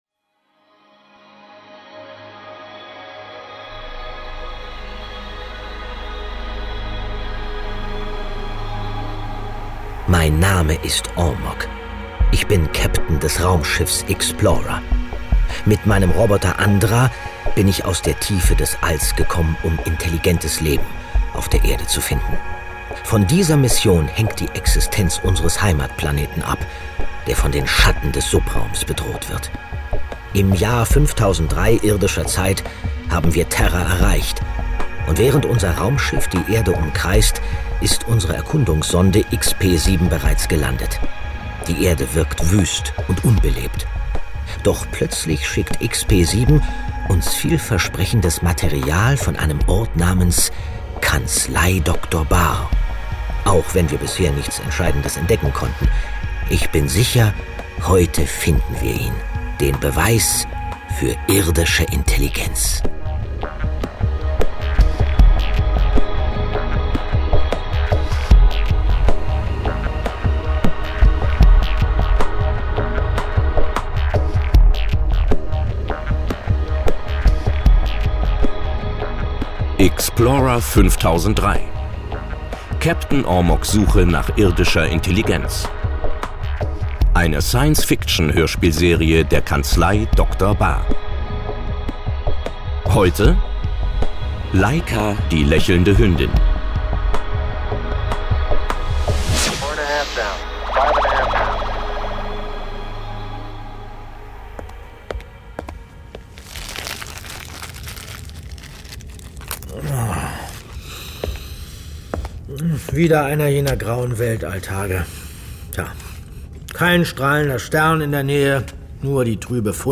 Vor kurzem ist die Science-Fiction-Hörspiele-Serie “Ixplorer 5003” der Kanzlei Dr. Bahr gestartet, in der in zwölf Hörspielfolgen von jeweils ca. 15 Minuten ein Dutzend Fragen des Online-Rechts an Beispielen erläutert und kommentiert werden.